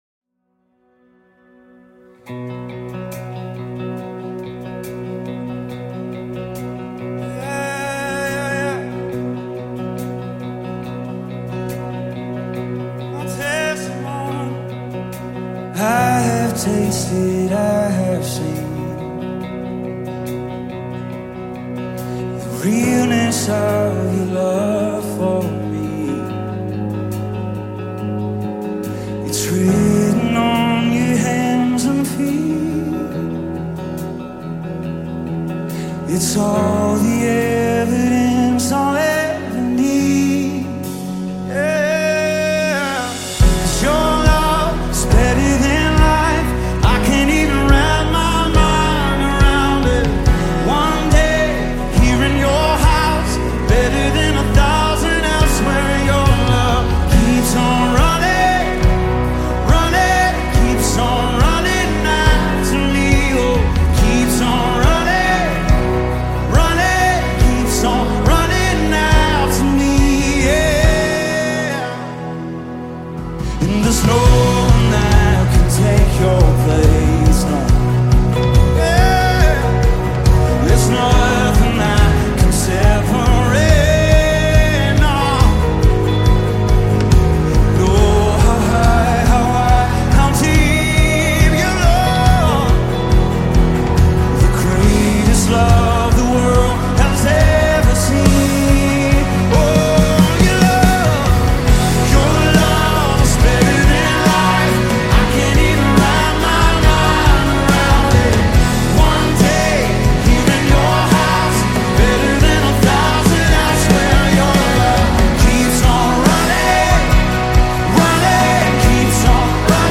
the acclaimed gospel music group